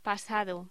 Locución: Pasado
voz